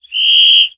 Silbato
aerófono pito viento aire atención llamada silbar silbato soplar